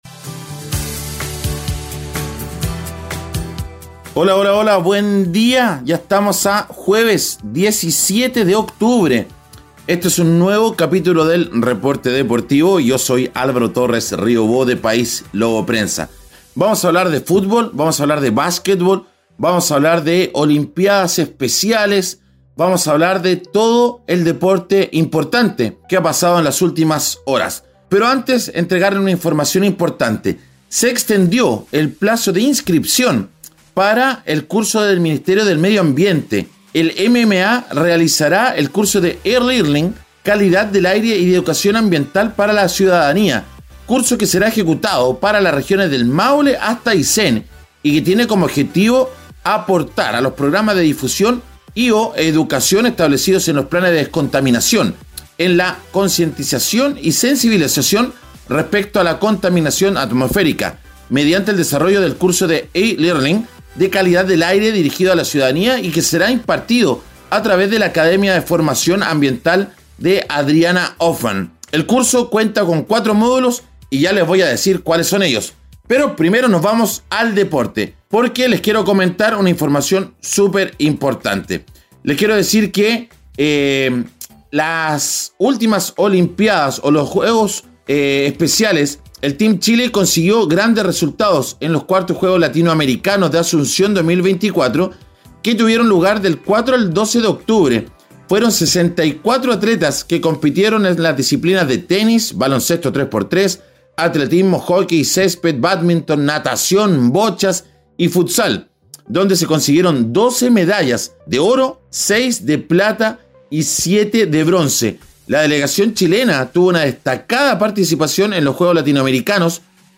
🇺🇾 El entrenador comparte sus impresiones.